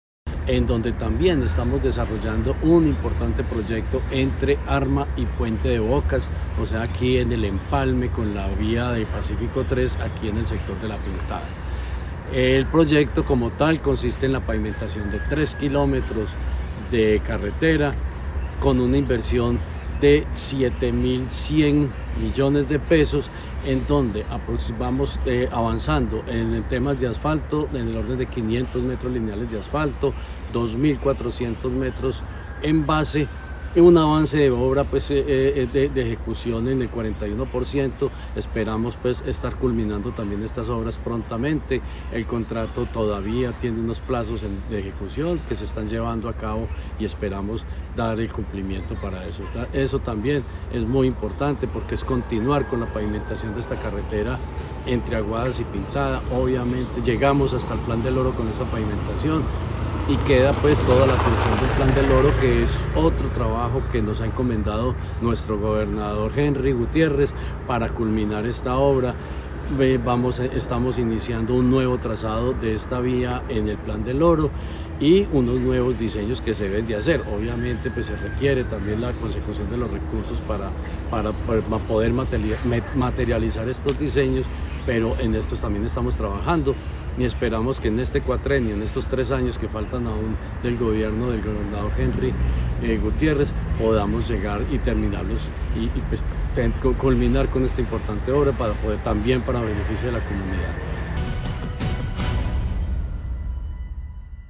Jorge Ricardo Gutiérrez, secretario de Infraestructura del departamento de Caldas.